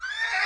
Horse Whinny Sound Effect Free Download
Horse Whinny